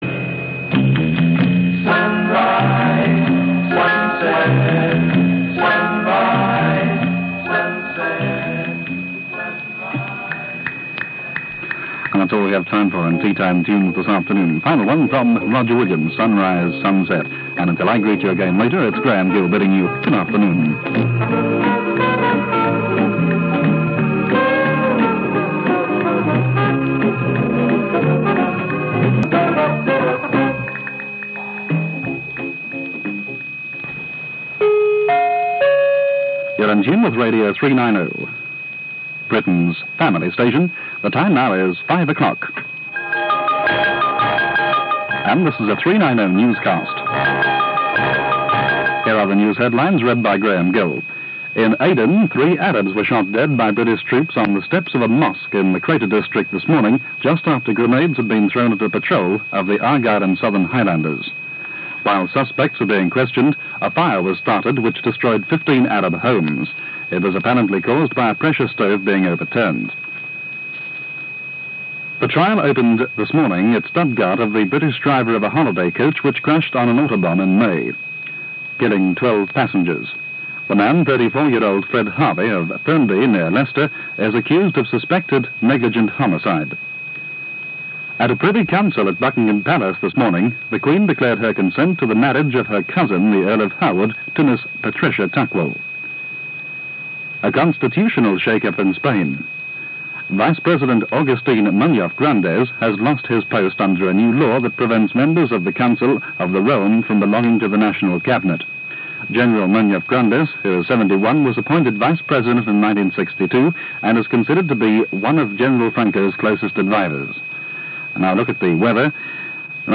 At 5.10pm the station played the National Anthem and Radio 390 closed for the final time.
Radio 390 closing down 28 July 1967.mp3